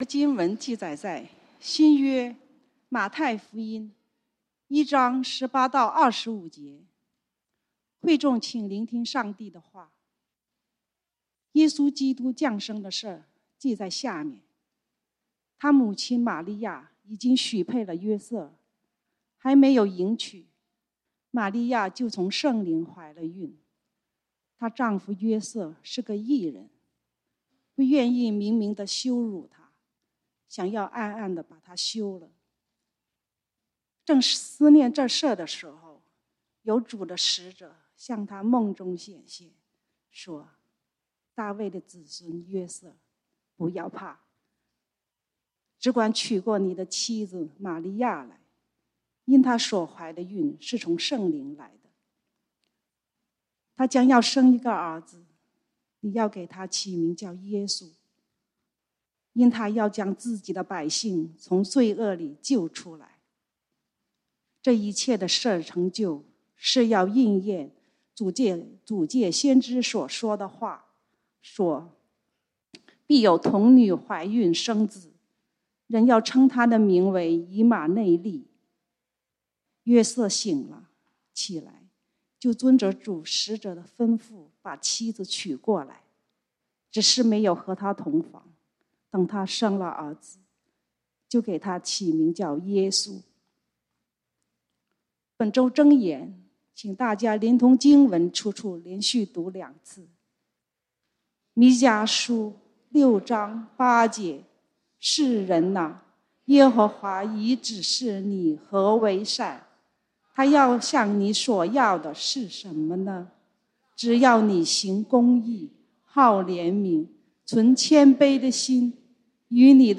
講道經文：馬太福音 Matthew 1:18-25 本週箴言：彌迦書 Micah 6:8 「世人哪，耶和華已指示你何為善。